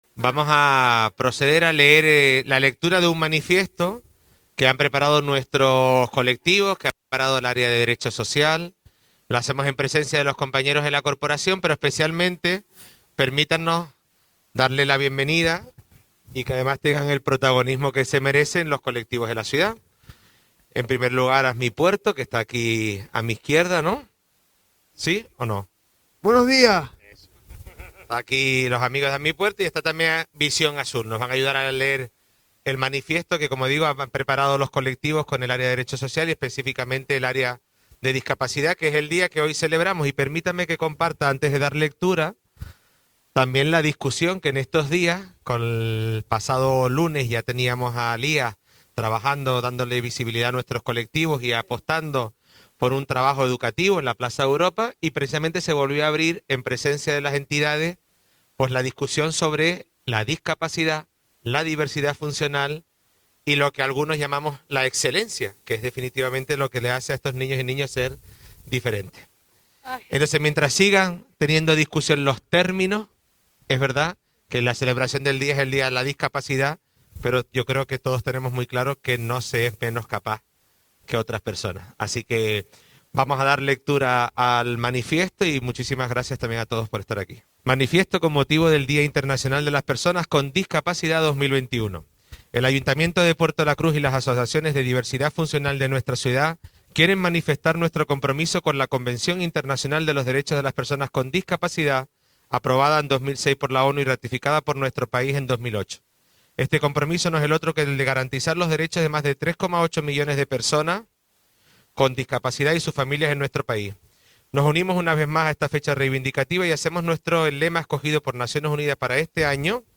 Puerto de la Cruz. Lectura Manifiesto Día Discapacidad. 3 de diciembre
puerto-de-la-cruz-lectura-manifiesto-dia-discapacidad-3-de-diciembre